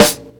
Snares
livingz_snr (1).wav